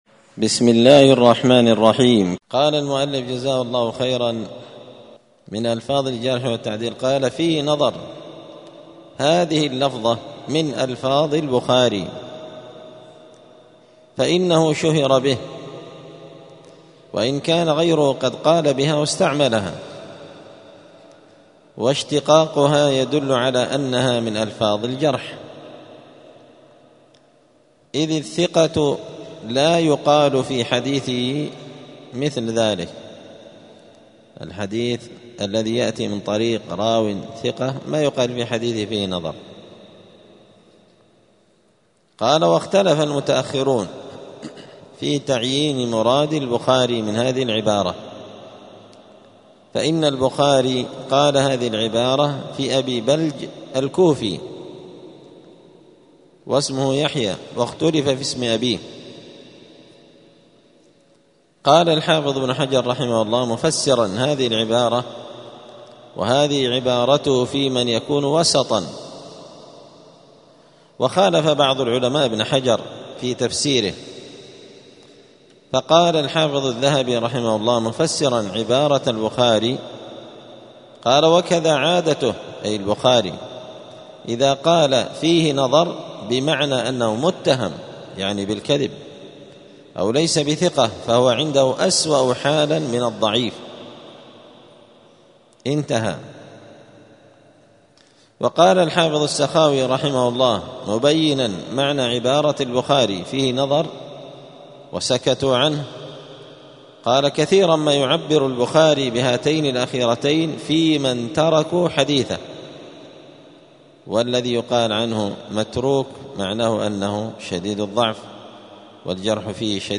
*الدرس الرابع والعشرون بعد المائة (124) باب من ألفاظ الجرح والتعديل {فيه نظر}*